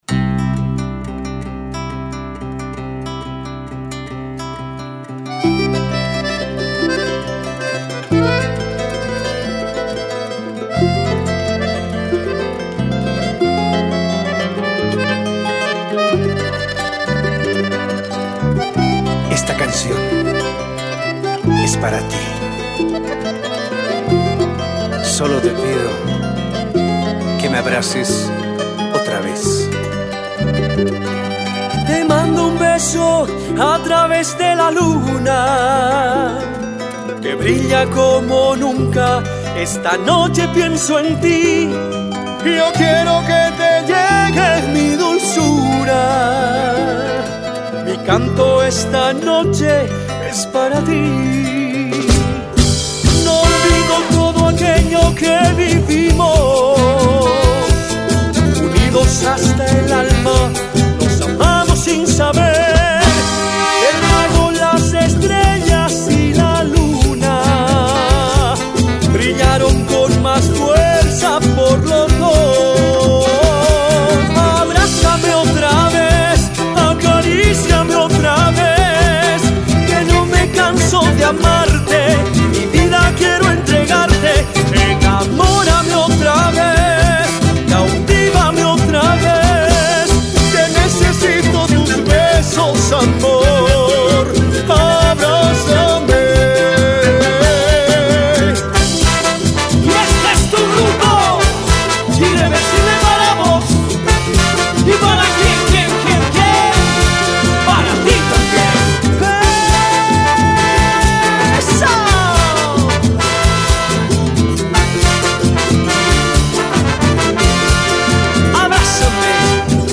Genre Caporal